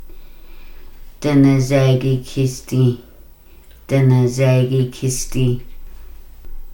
Individual audio recordings of Kaska words and phrases about learning language. This subset of the original Helping Language Deck demonstrates how to ask what an object is in Kaska.